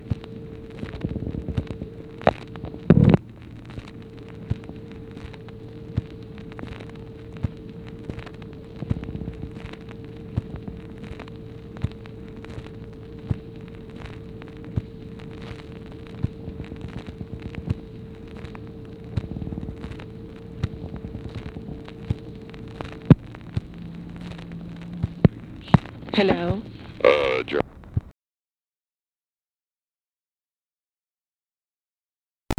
Conversation with OFFICE SECRETARY and GEORGE REEDY, April 9, 1964
Secret White House Tapes | Lyndon B. Johnson Presidency